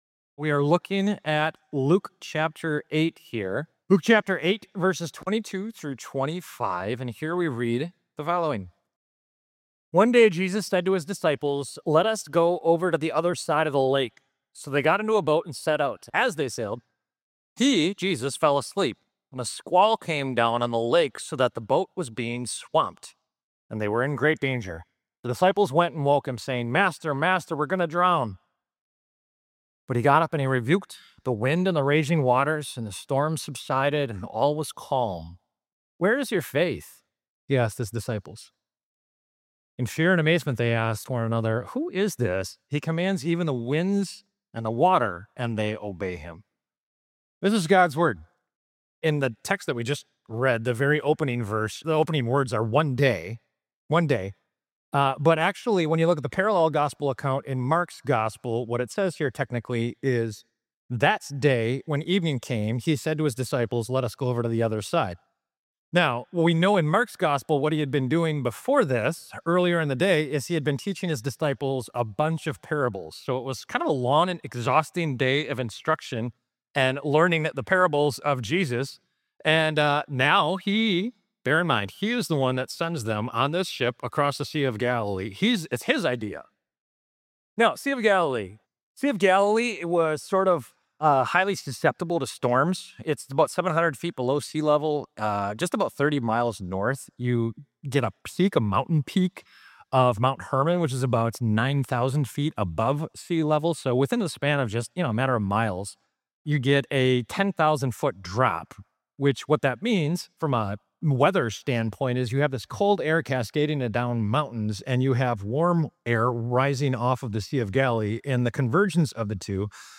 St Marcus MKE Sermons « » Kindness to Friends and Those in Need | David: The Struggles of a Faithful, but Fallen King